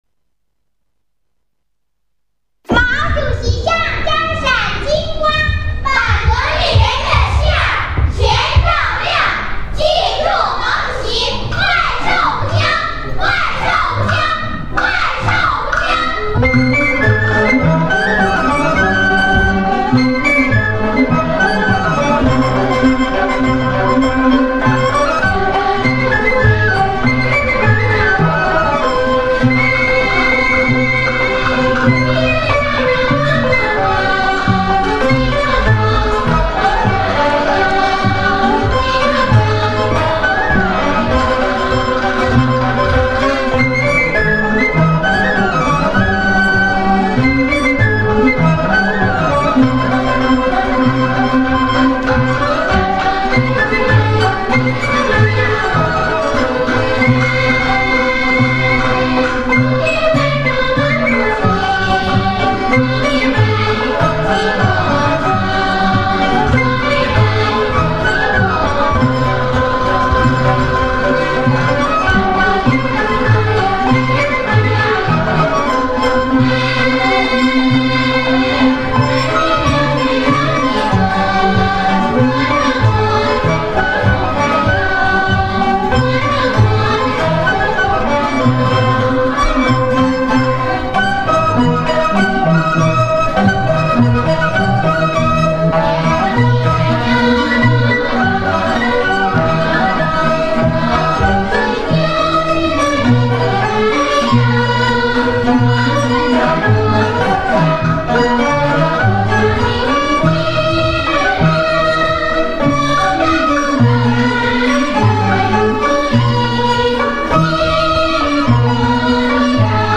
音乐是西藏风格的